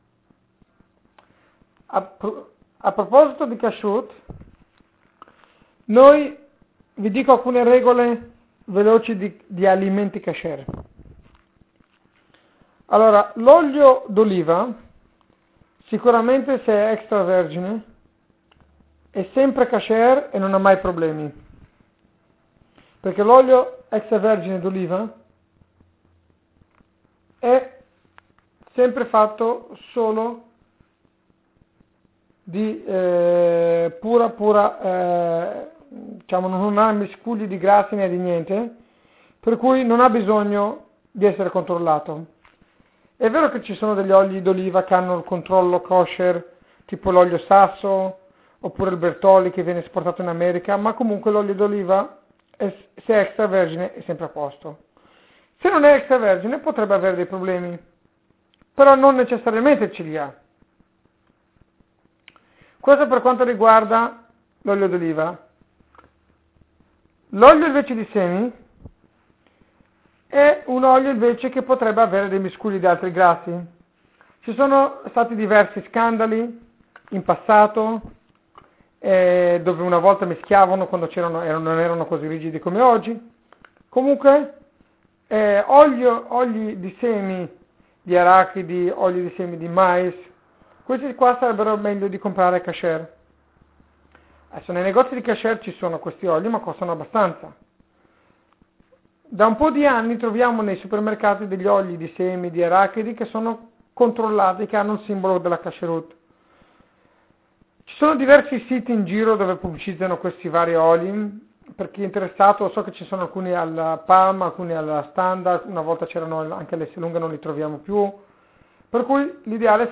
La lezione del 2 febbraio 2006 sulla parasha Bo